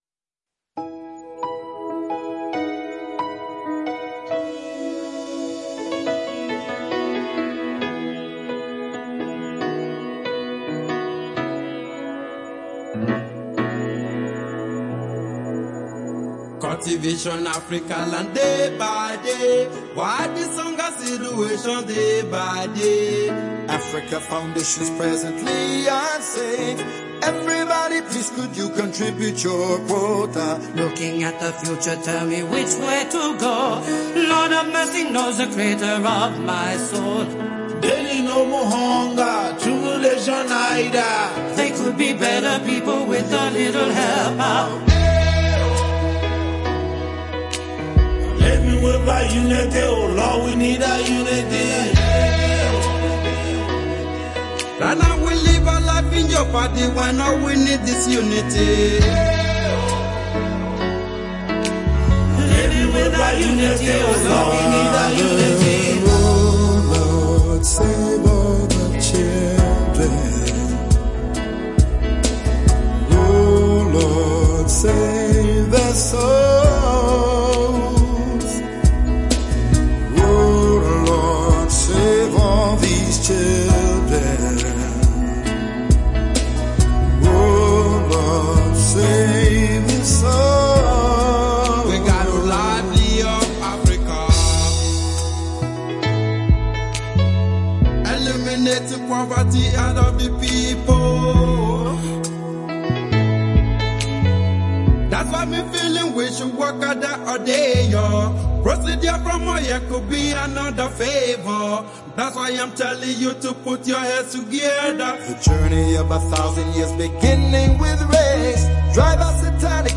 Choir arrangements and vocals